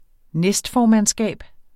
Udtale [ ˈnεsdˌfɒːmanˌsgæˀb ]